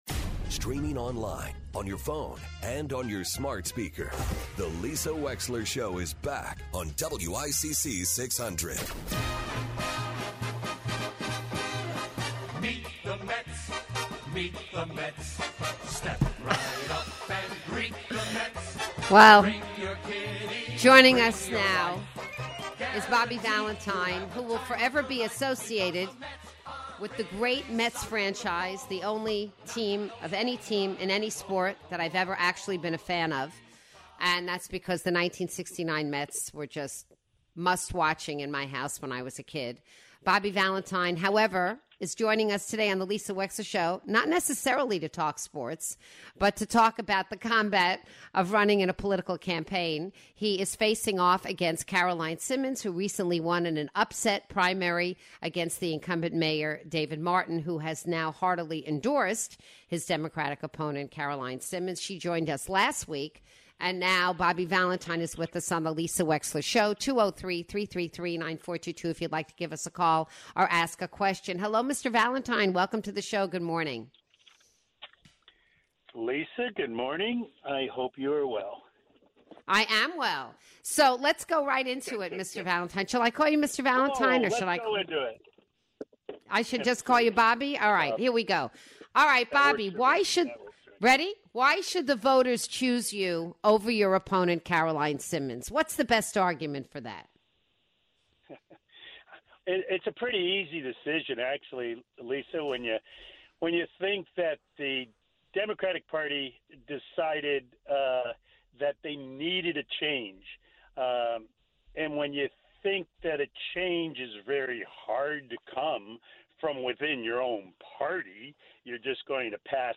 Former MLB manager and candidate for Mayor of Stamford Bobby Valentine joins the show to discuss the upcoming election.